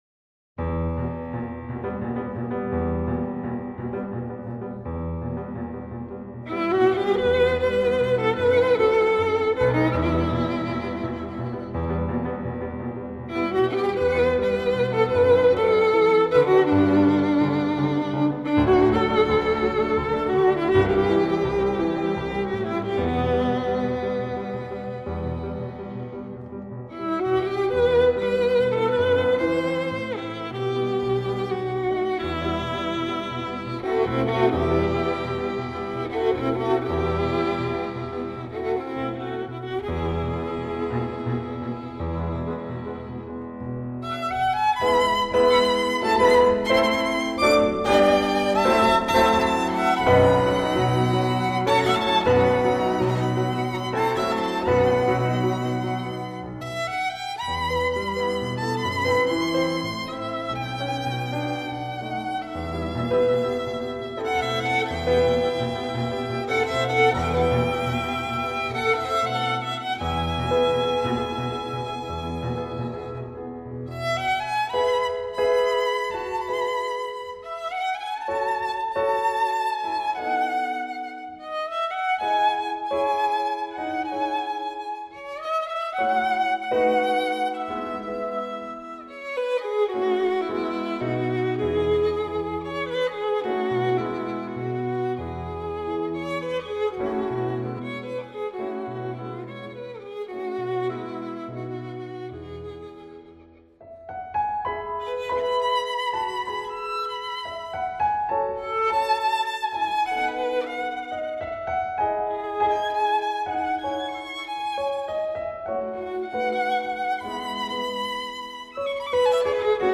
曲调优美，演奏细腻，丝丝入扣
爆发力强，有着惊人的速度和技巧